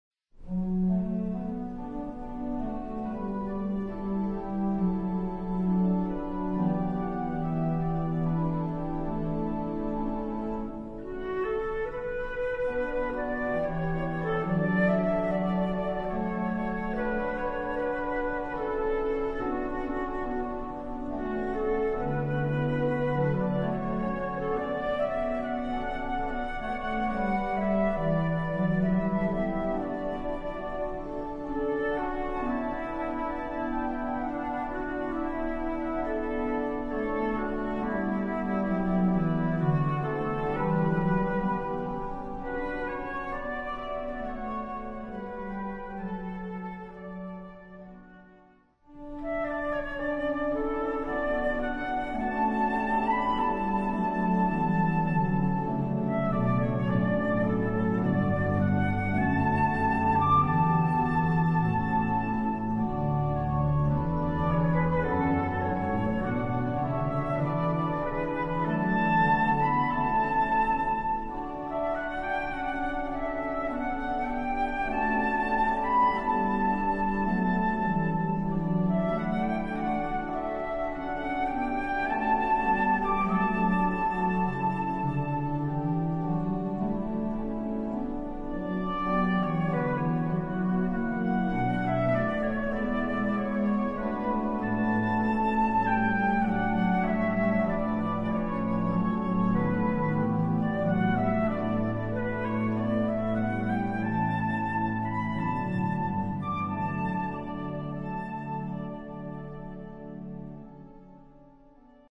Voicing: Flute and Organ